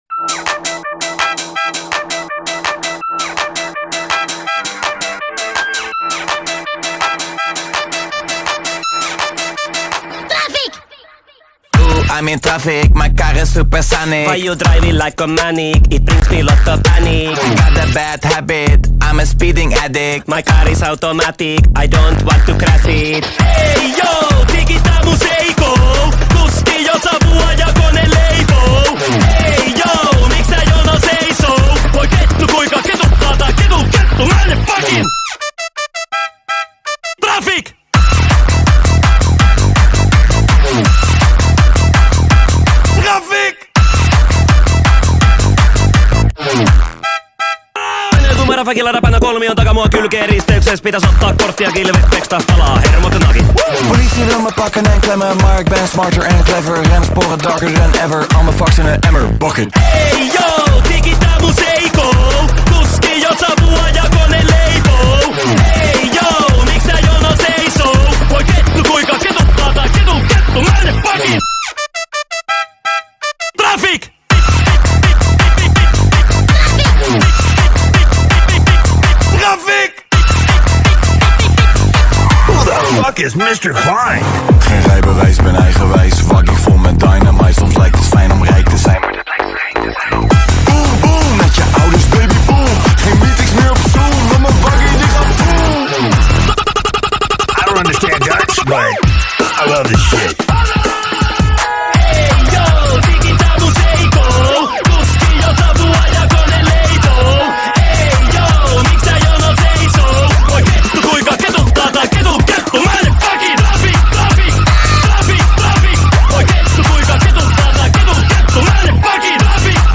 BPM82-165
Audio QualityPerfect (High Quality)